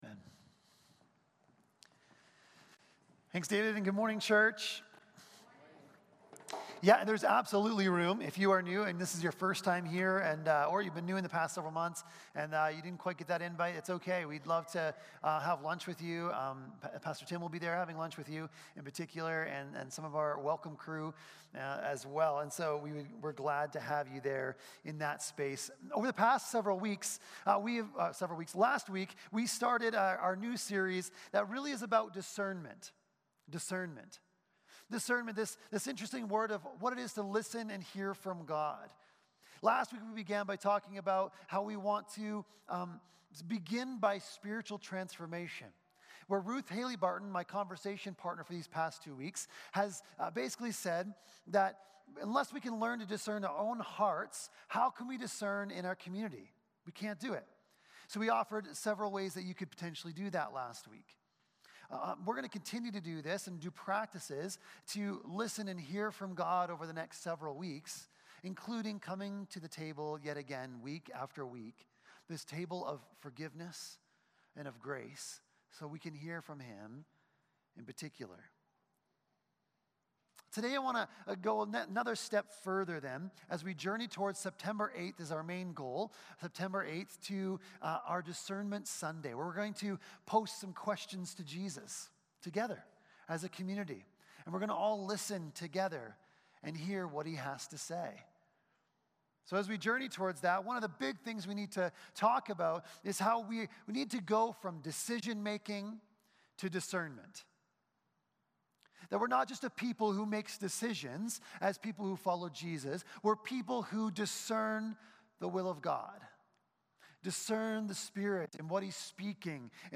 19-30 Service Type: Sunday Morning Service Passage